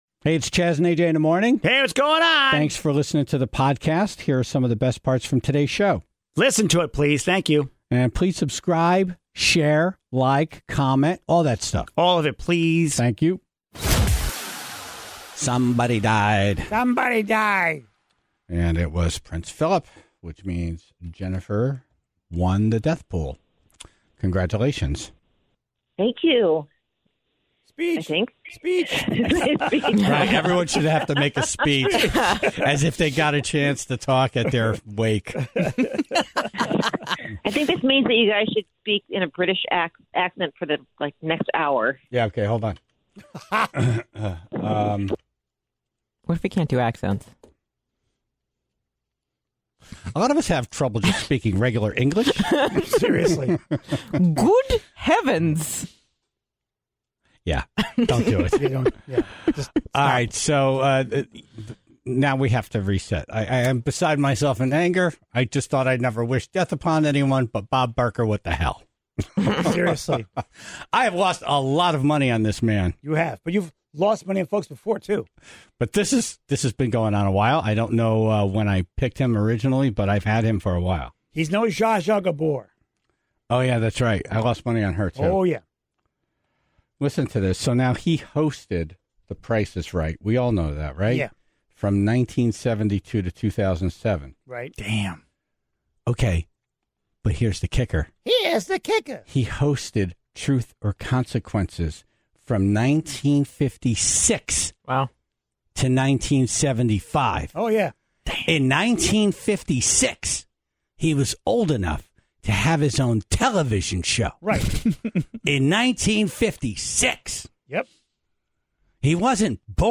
Fauci was on the phone to share his thoughts on everything from the pandemic, to having a healthy sex life.